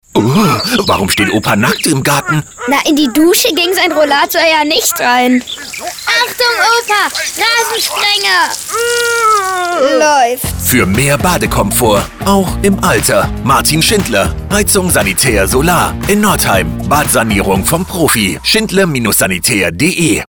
A modern, warm and dynamic voice equally at home in his native German or english
Commercial, Bright, Upbeat, Conversational